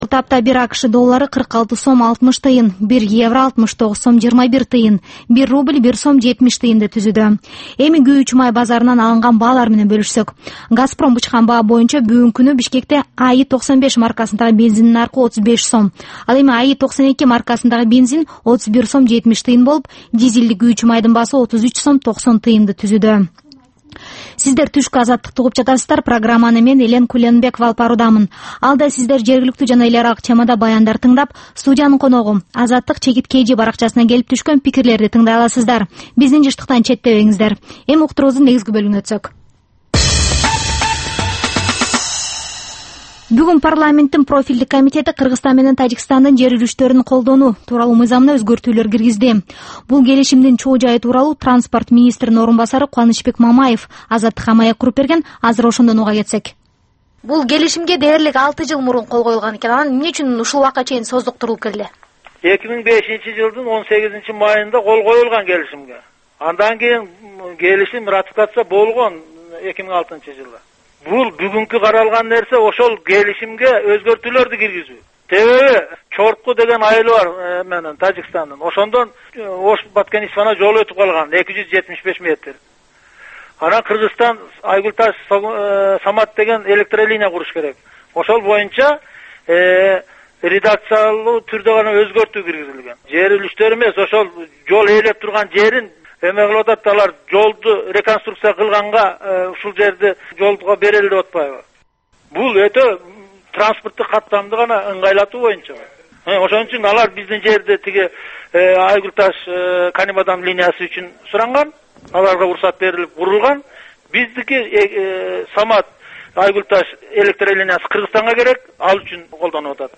Бул түшкү үналгы берүү жергиликтүү жана эл аралык кабарлар, ар кыл орчун окуялар тууралуу репортаж, маек, талкуу, кыска баян жана башка оперативдүү берүүлөрдөн турат. "Азаттык үналгысынын" бул түш жаңы оогон учурдагы берүүсү Бишкек убакыты боюнча саат 13:00төн 13:30га чейин обого чыгарылат.